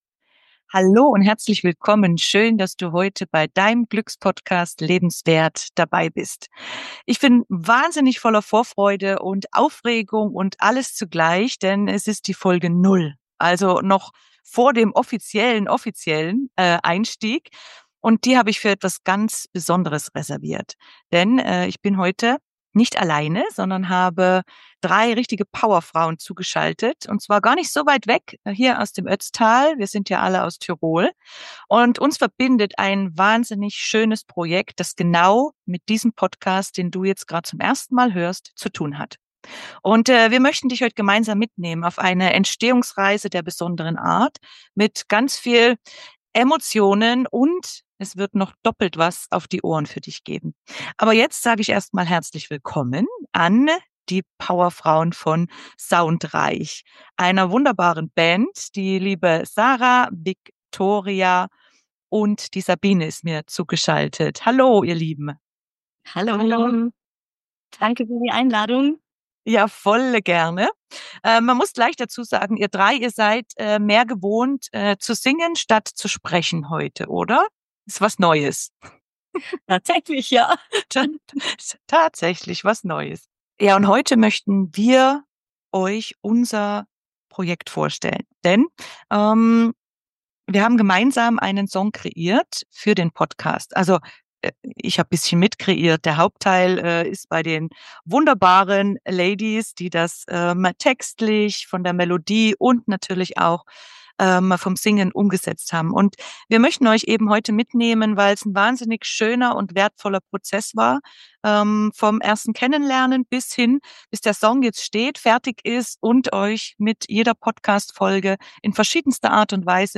000Interview_SounDREIch_Podcast_Song_FERTIG_mit_Auphonic.mp3